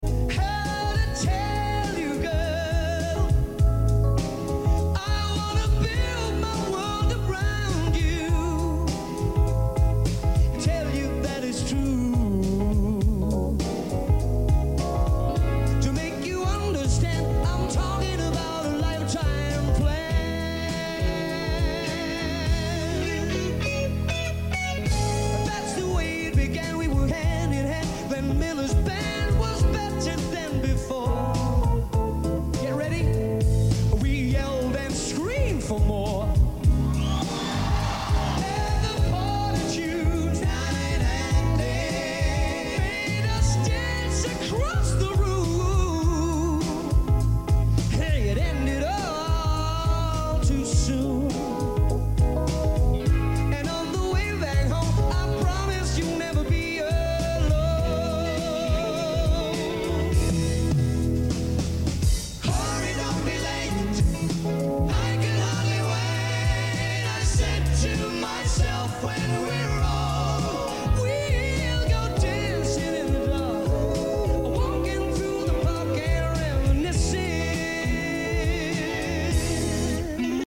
soft rock classics